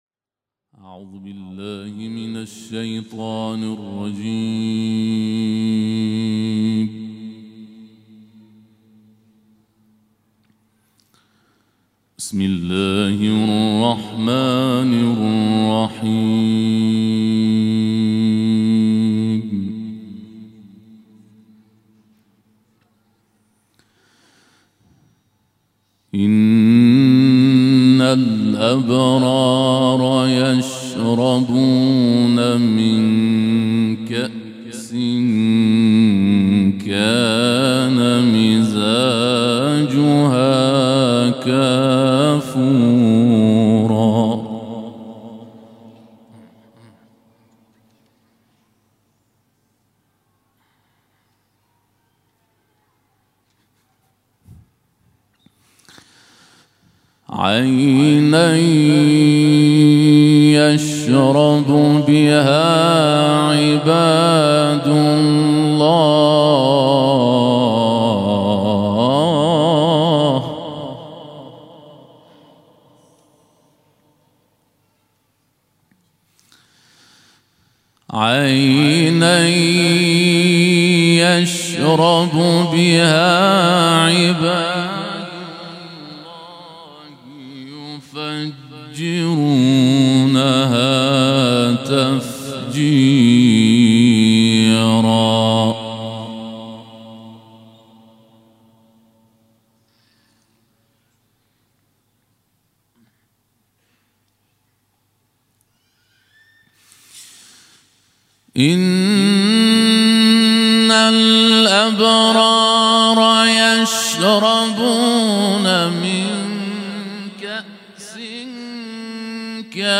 شب قدر سوم 1440 - تلاوت مجلسی
رمضان 1440 بفرمایید مهمانی خدا مسجد جامع یزد شبهای قدر